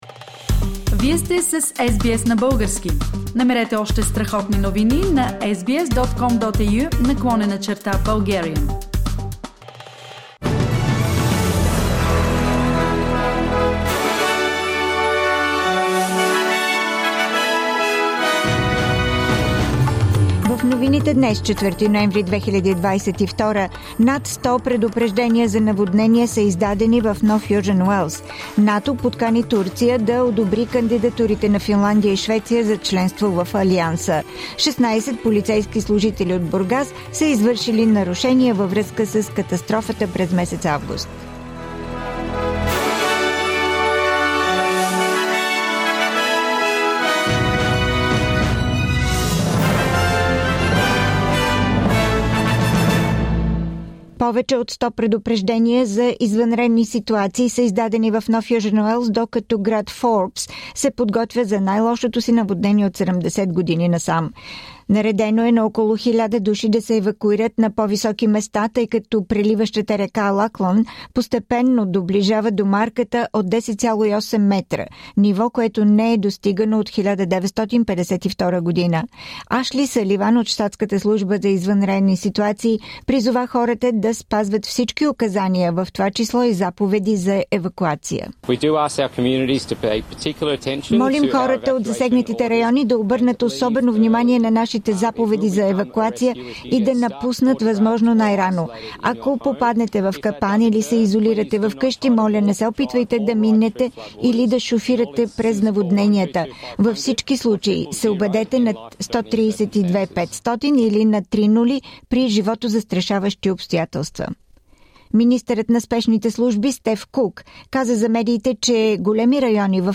Weekly Bulgarian News – 4th November 2022